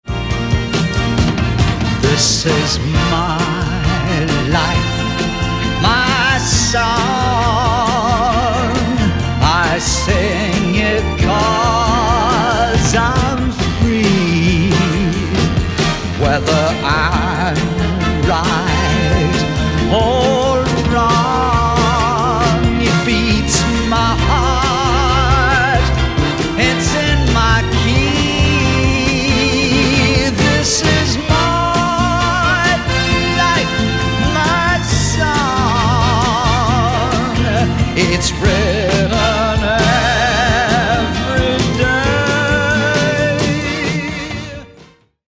Lavishly orchestrated